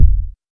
Kicks
KICK.106.NEPT.wav